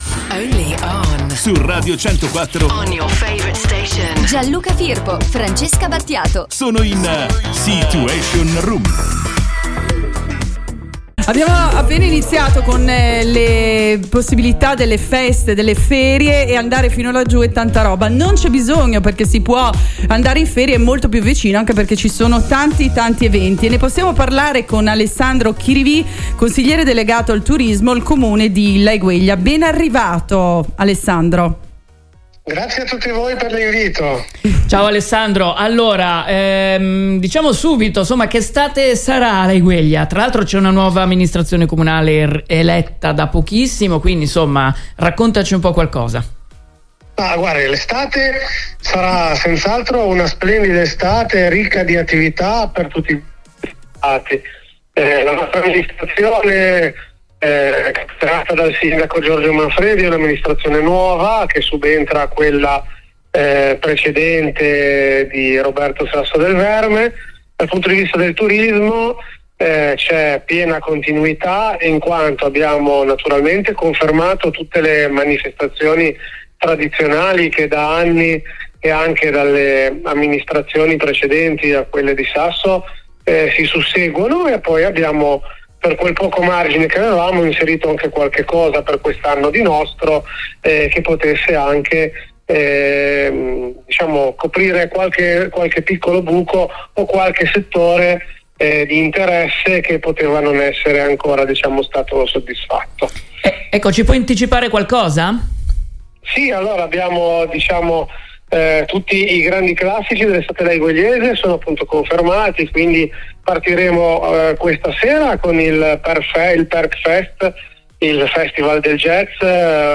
Alessandro Chirivì consigliere delegato al turismo Laiguelia ci parla degli eventi che ci saranno